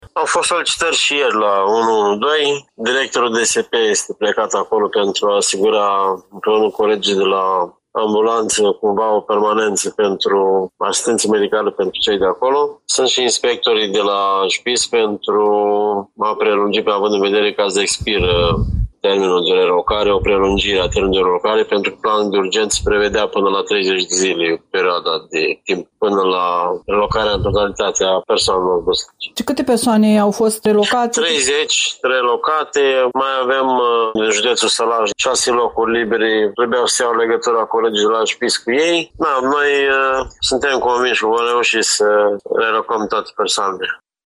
Prefectul Dan Nechifor: